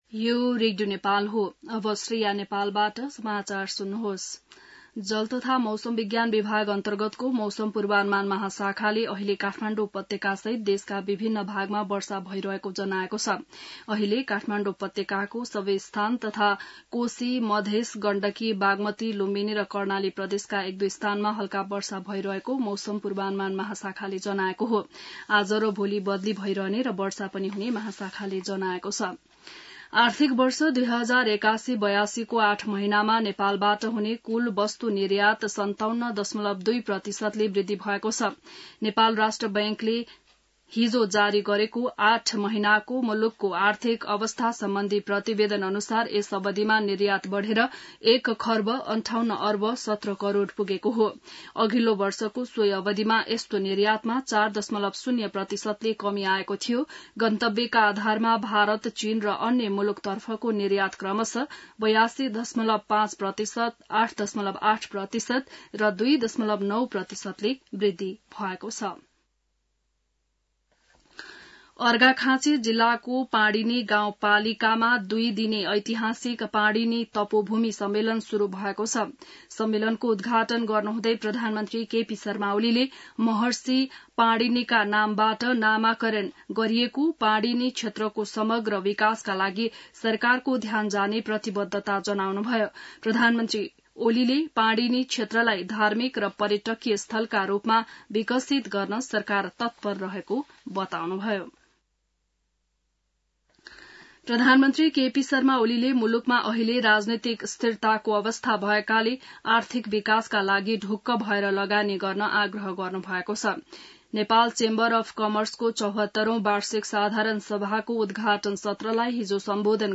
बिहान ६ बजेको नेपाली समाचार : २८ चैत , २०८१